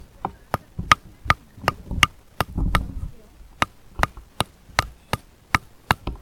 두드리는02.mp3